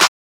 Southside Clap (5).wav